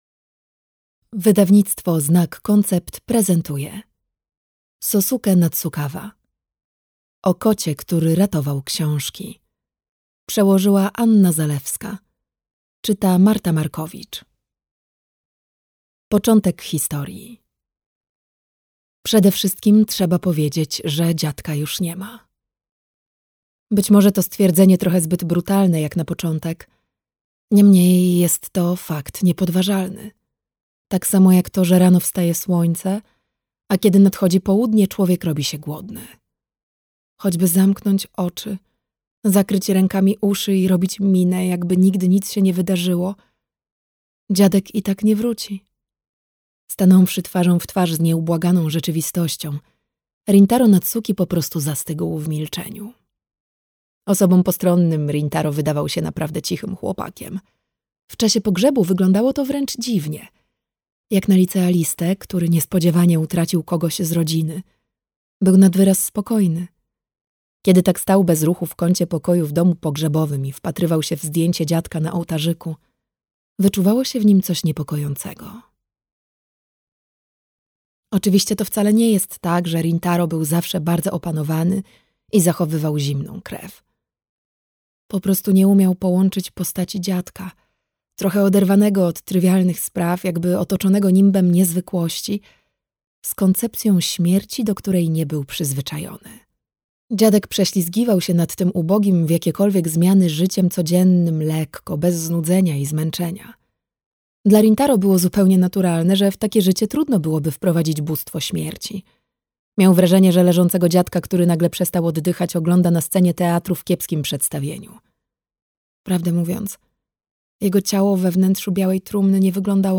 O kocie, który ratował książki - Sosuke Natsukawa - audiobook + książka